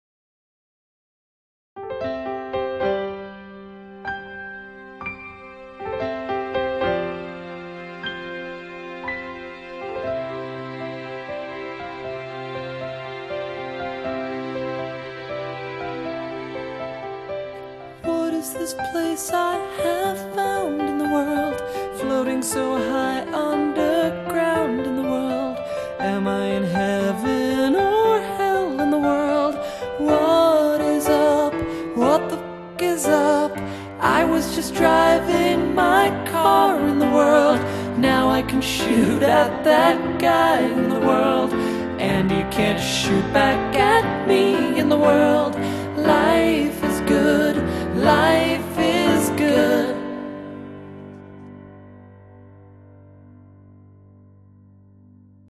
Also, against all logic and reason, this blog post actually has a deleted song — a touching, soulful, tear-jacking ballad called